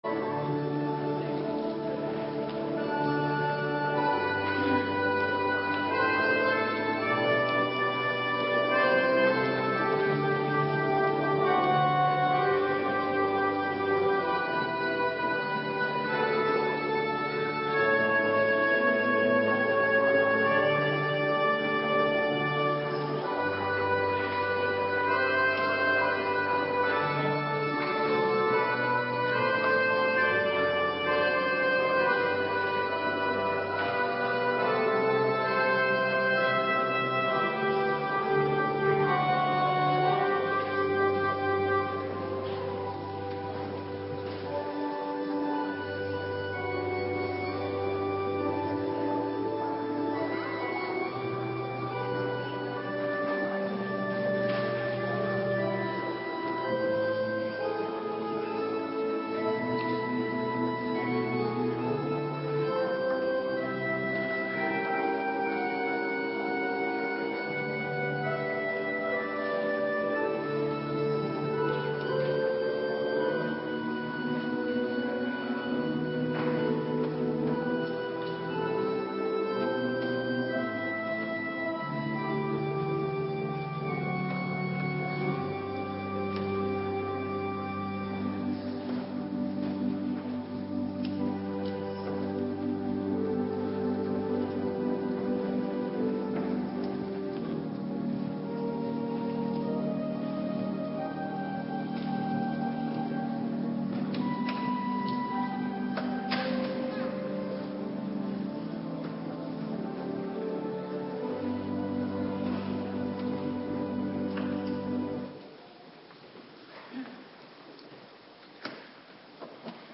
Morgendienst - Cluster A
Locatie: Hervormde Gemeente Waarder